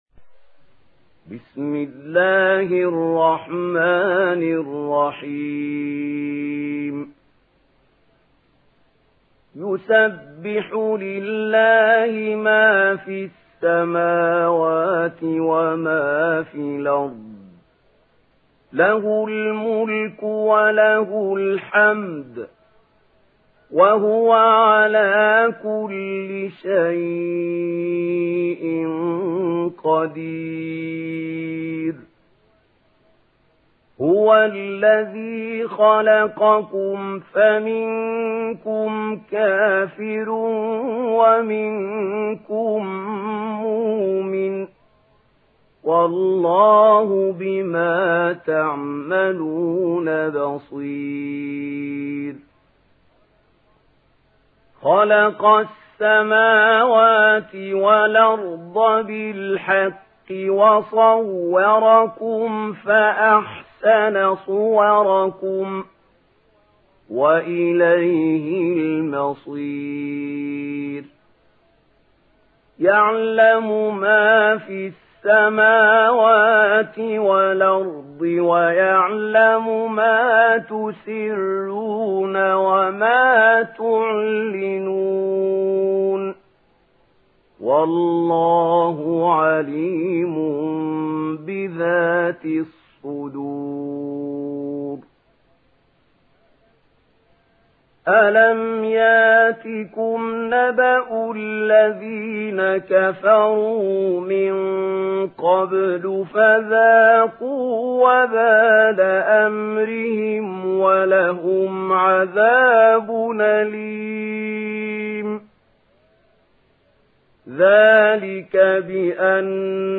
تحميل سورة التغابن mp3 بصوت محمود خليل الحصري برواية ورش عن نافع, تحميل استماع القرآن الكريم على الجوال mp3 كاملا بروابط مباشرة وسريعة